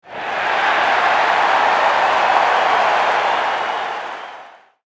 Cheers.mp3